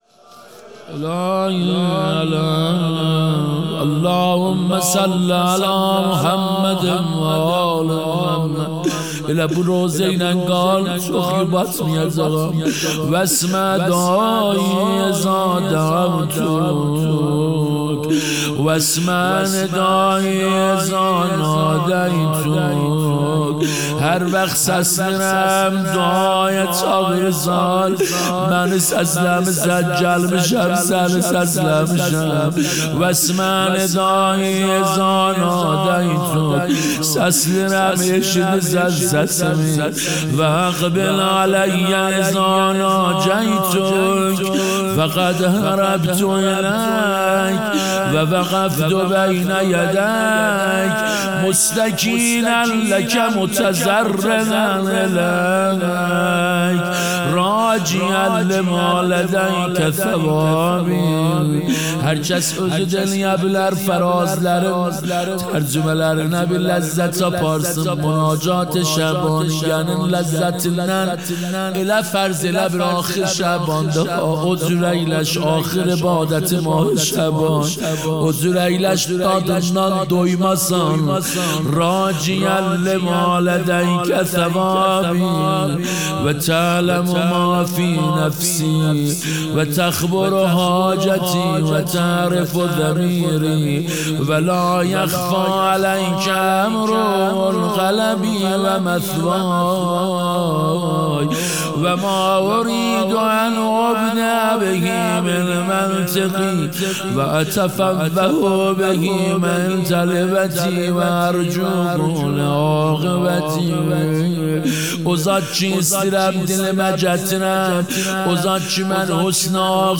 دعاخوانی